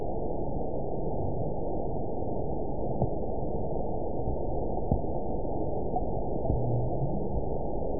event 920399 date 03/23/24 time 00:06:45 GMT (1 year, 1 month ago) score 5.34 location TSS-AB02 detected by nrw target species NRW annotations +NRW Spectrogram: Frequency (kHz) vs. Time (s) audio not available .wav